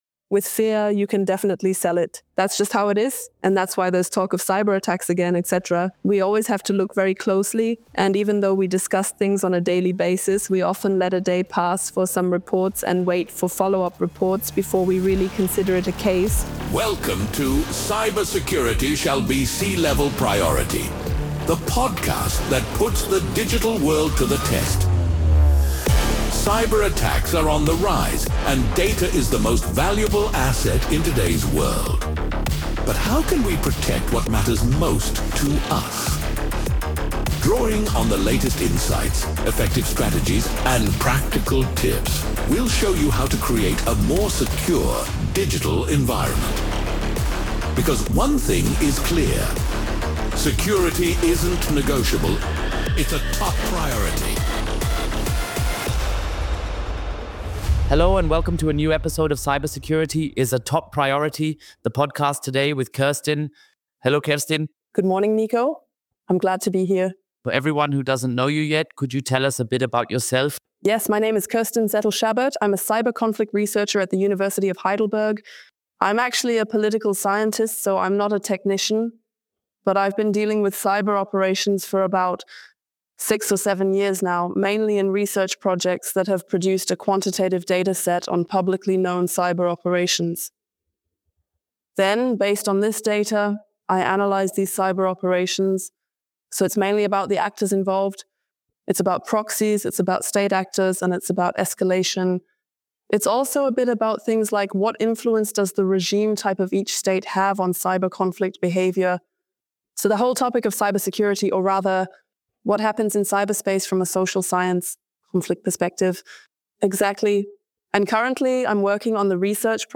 LinkedIn-Profile ____________________________________________ 🚨 This Podcast is translated from the original content Cybersecurity ist Chefsache using AI technology to make them accessible to a broader audience.